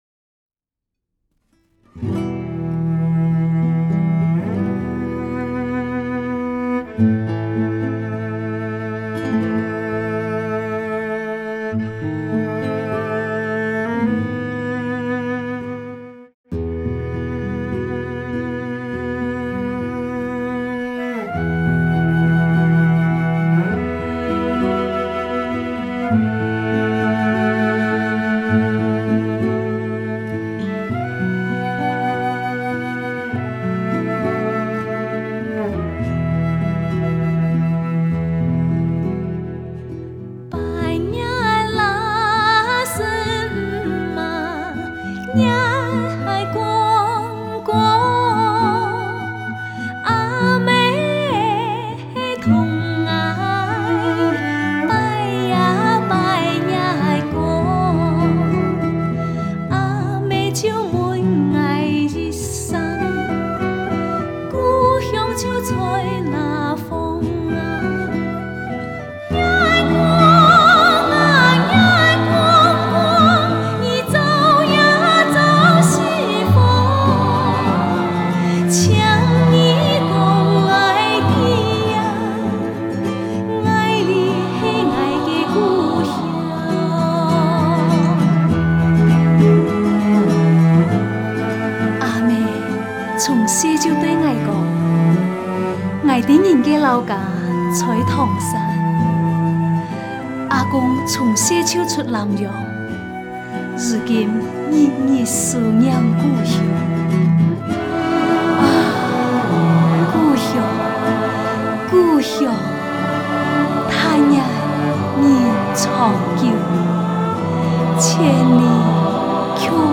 经过适当的整理和艺术加工，使音乐既能保持原先的质朴与天趣，也适应都市人的欣赏品味。
乡土气息浓郁地道